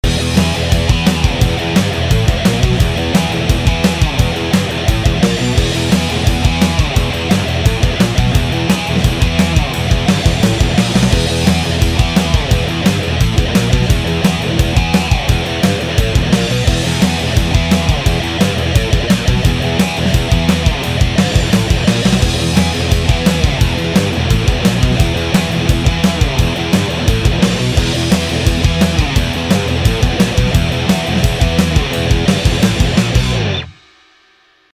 Хамбакеры
Вот, как обещал. 3 гитары. По 8 тактов.
Использовал Steinberger (с EMG), JP60, Strat US Plus. Гитары не в том порядке на записи.